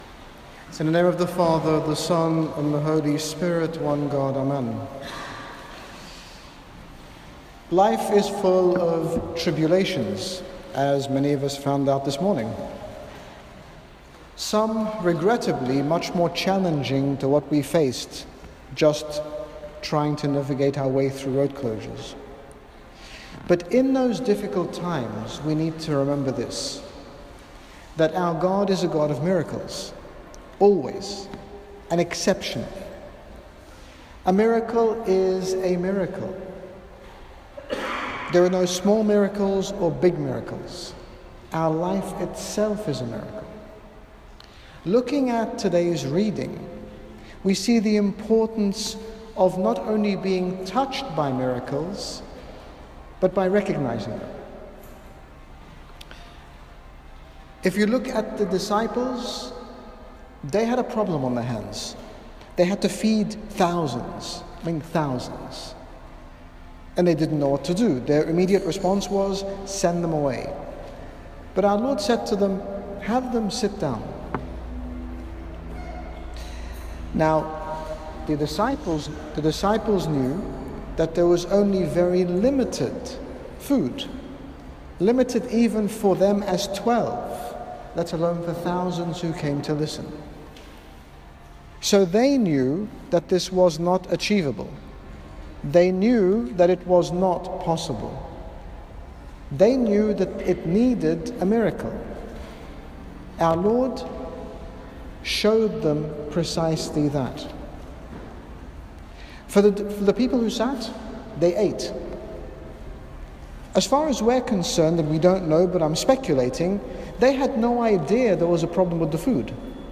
In this short sermon, His Grace Bishop Angaelos, General Bishop of the Coptic Orthodox Church in the United Kingdom, speaks to us about the importance of appreciating the miracles in our lives, and the constant provision God offers us all, that we often take for granted.